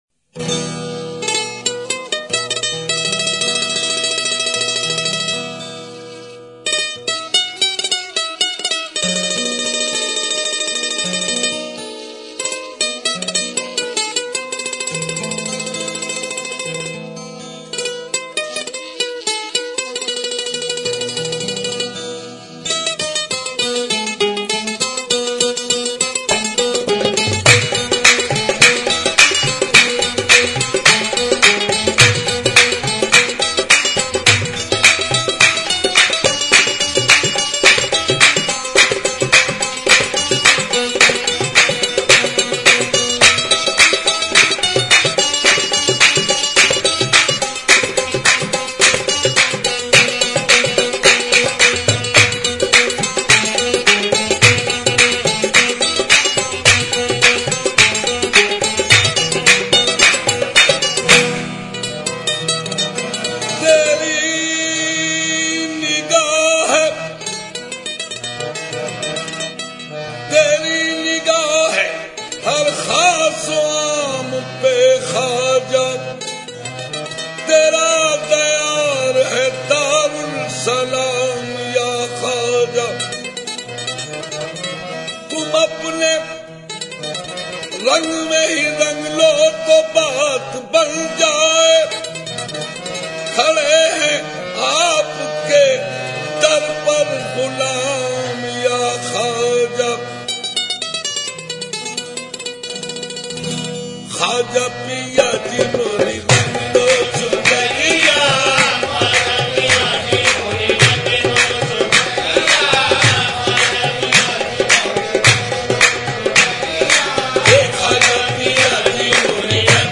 Islamic Qawwalies And Naats